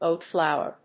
oat+flour.mp3